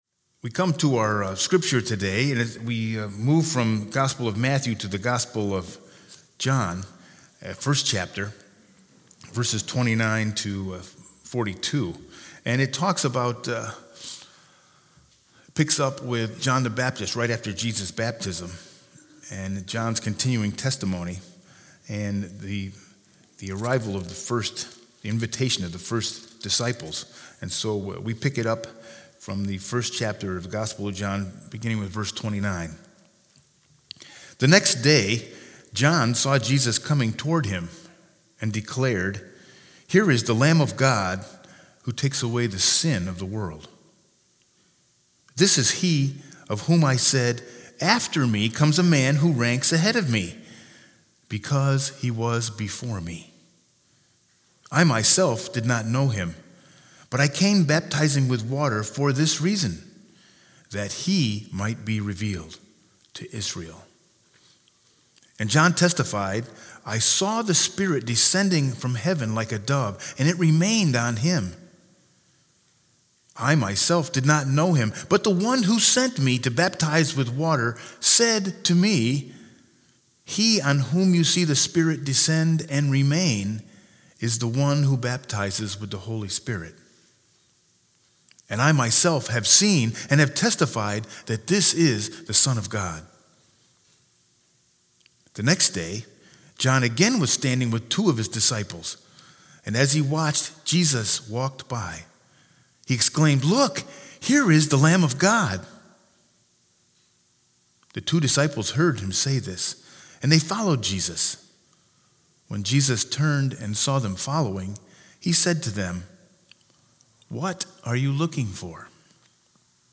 Sermon 1-19-20 with Scripture Lesson John 1_29-42